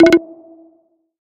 menu-edit-click.ogg